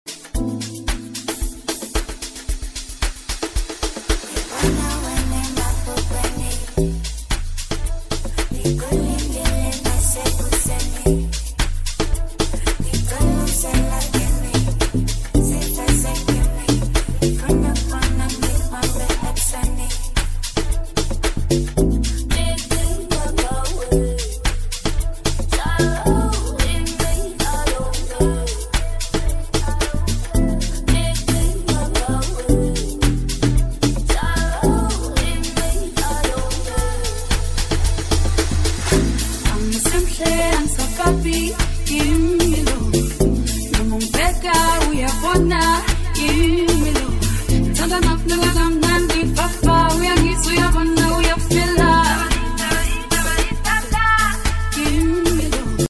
VOICE OF WITS FM INTERVIEW!